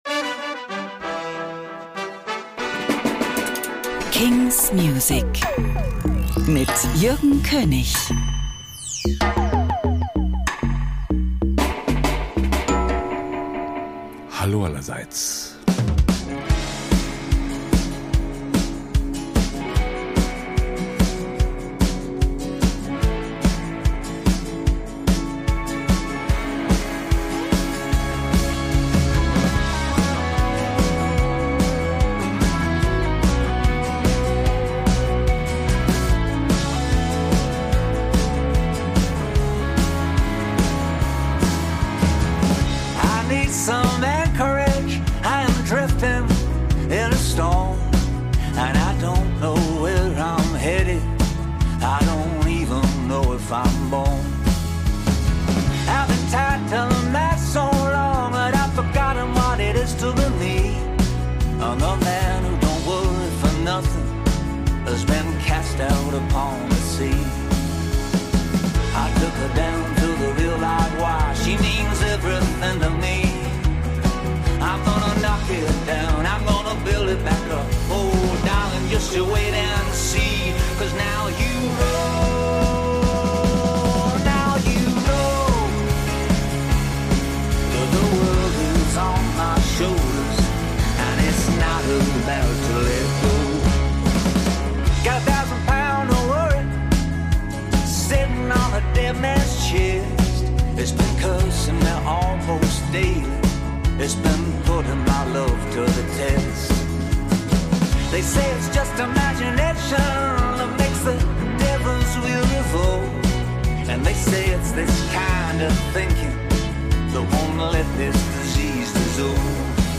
new indie & alternative releases.